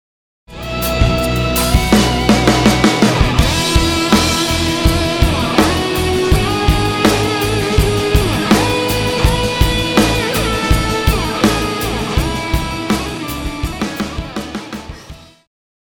套鼓(架子鼓)
乐团
流行音乐
演奏曲
摇滚
独奏与伴奏
有节拍器
以明快的节奏搭配激情奔放的吉他狂飙，